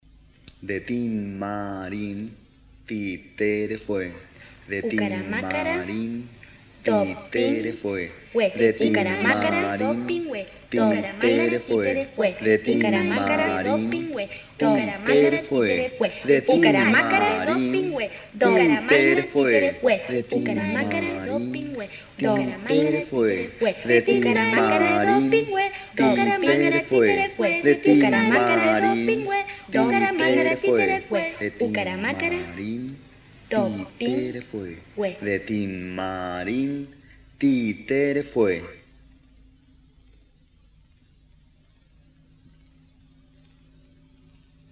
Rima de Sorteo tradicional